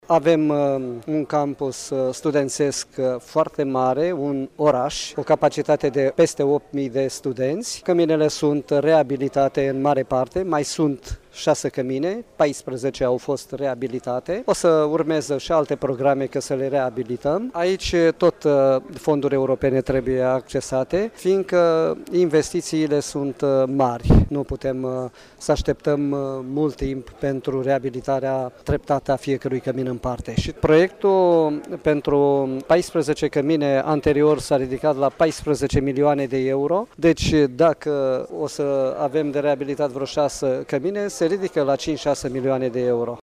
(AUDIO) La Universităţile „Gh Asachi” şi „Petre Andrei” din Iaşi a avut loc astăzi deschiderea noului an academic